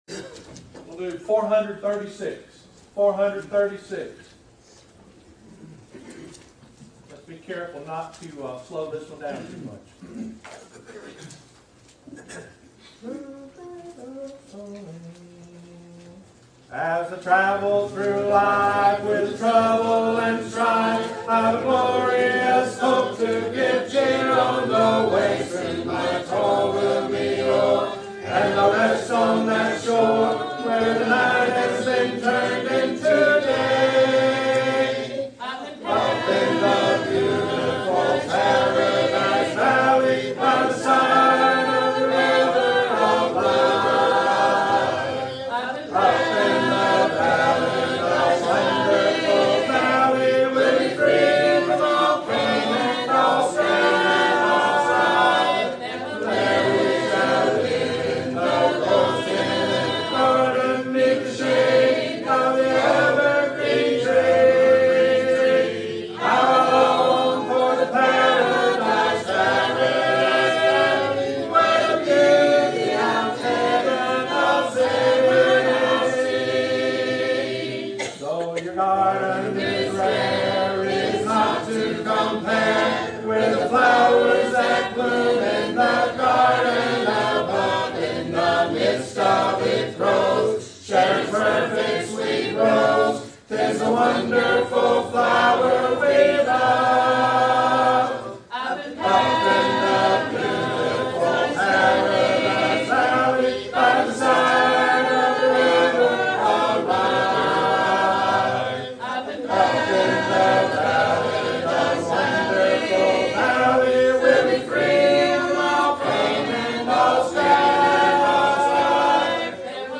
Mount Carmel Road Congregation SingingOthercongregational singing, a cappella singing